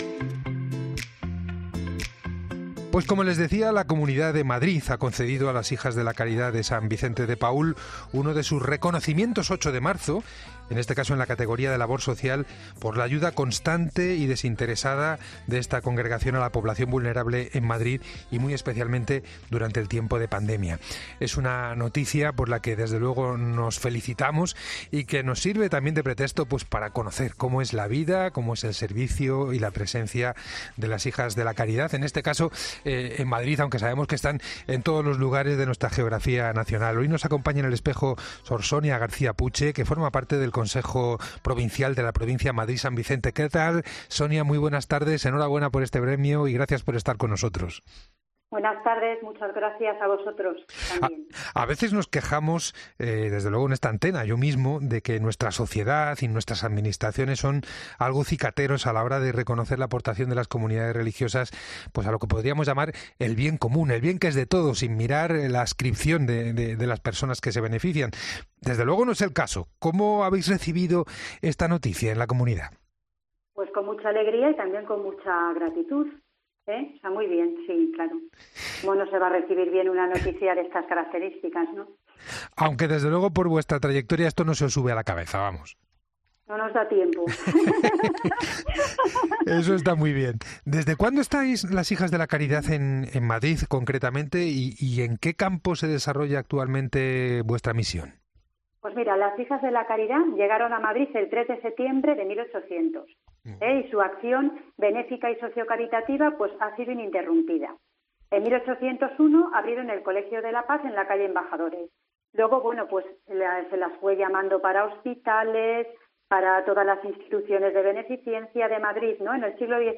La religiosa resume también parte de la historia de la Congregación en Madrid: "Las hijas de la caridad llegaron a Madrid en 1800 y su acción ha sido ininterrumpida desde entonces.